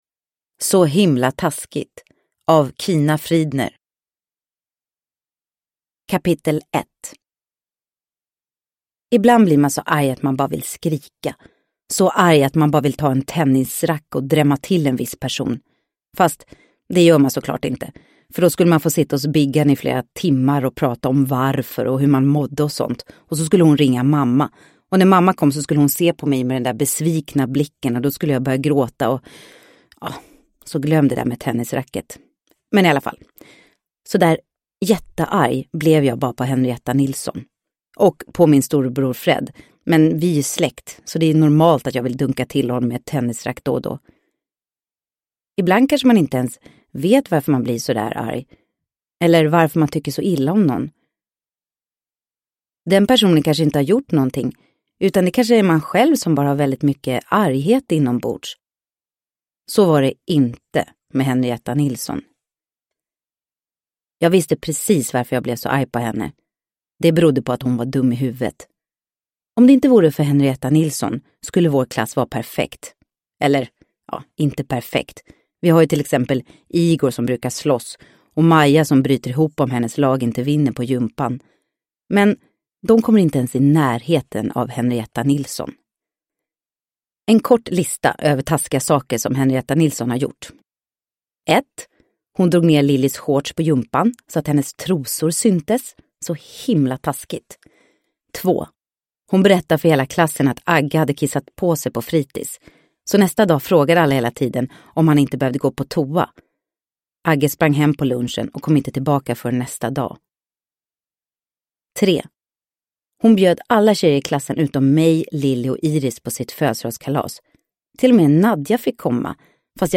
Så himla taskigt – Ljudbok – Laddas ner